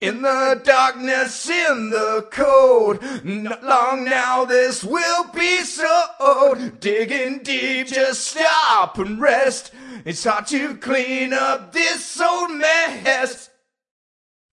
描述：即时摇滚歌手，只需添加音乐。 我认为这是在C和它在140 bpm。
声道立体声